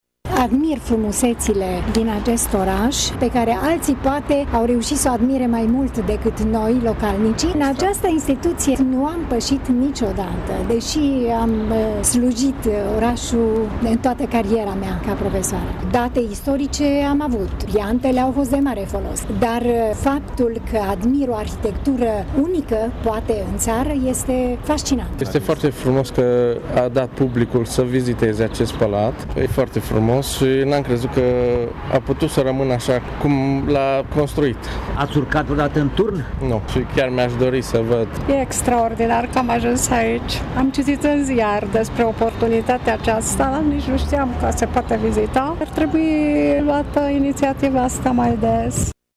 Unii recunosc că, fără această inițiativă, ajungeau să trăiască o viață în Tg. Mureș și nu intrau în Palatul Administrativ: